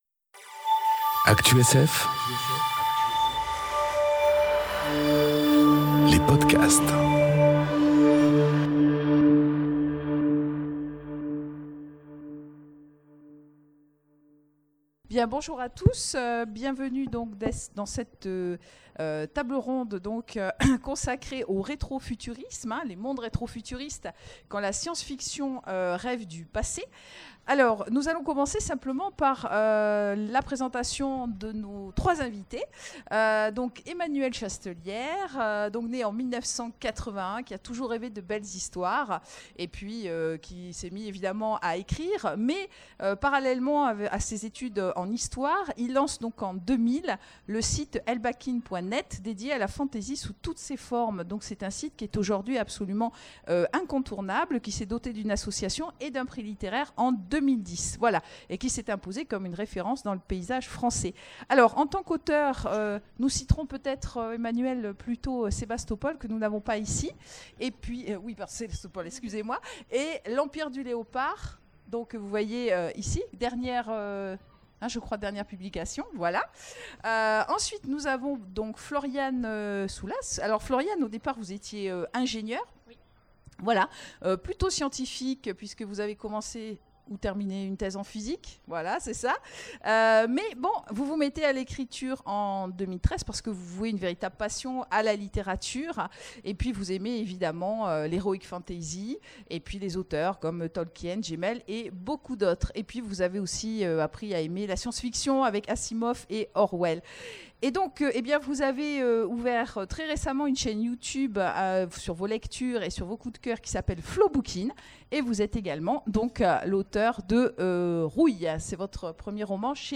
Imaginales 2018 : Conférence Les mondes rétro-futuristes... Quand la SF rêve du passé